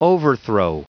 Prononciation du mot overthrow en anglais (fichier audio)
Prononciation du mot : overthrow